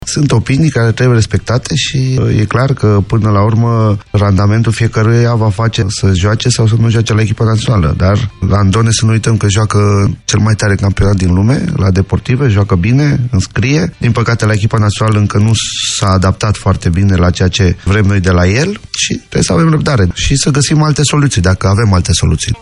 Cunoscut pentru temperamentul lui efervescent, tehnicianul a declarat la Europa FM în emisiunea “Tribuna Zero” că va mai avea răbdare cu doi dintre jucătorii-problemă.